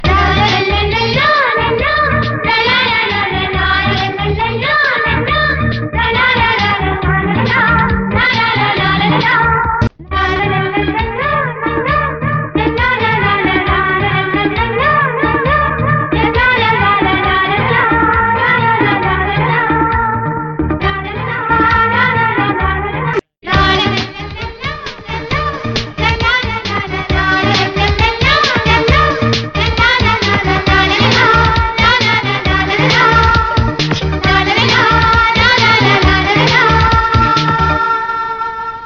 🎶 Humming Ringtone.mp3